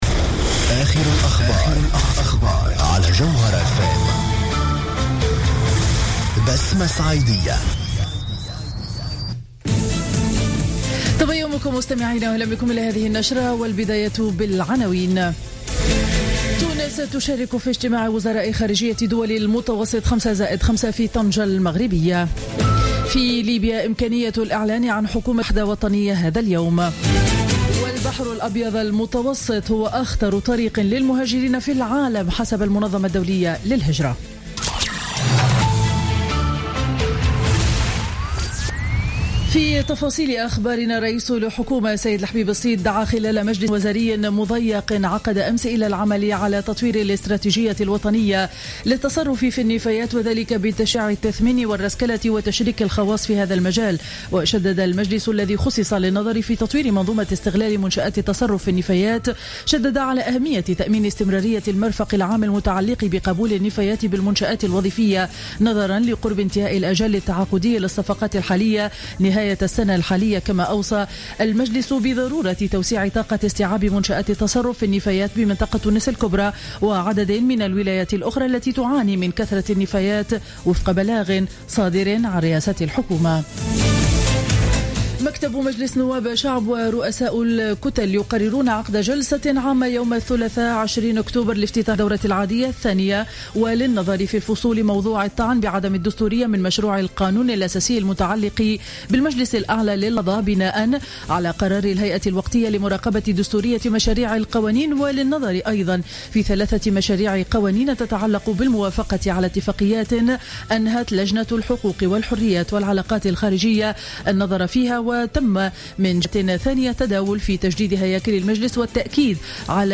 Journal Info 07h00 du mercredi 7 octobre 2015